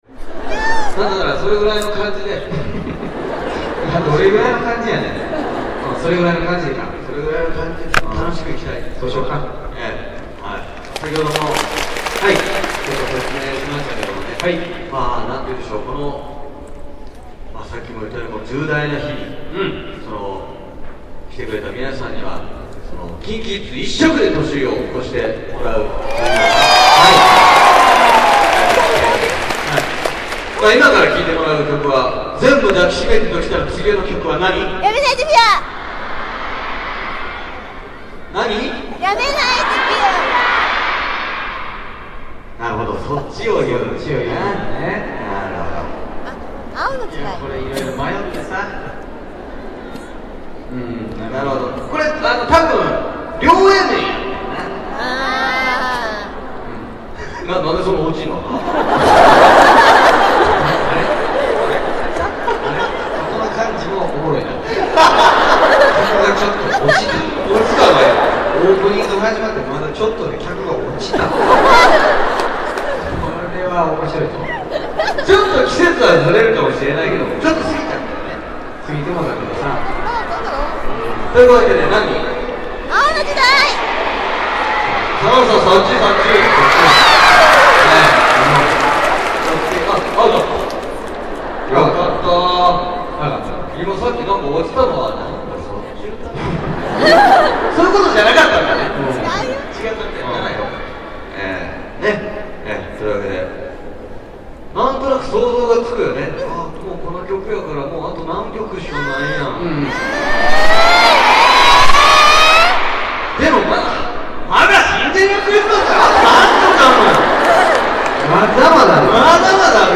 ※ 전에도 한 번 말씀드렸지만.... 제 옆의 팬분의 목소리도 장난없이 들립니다ㅋㅋㅋ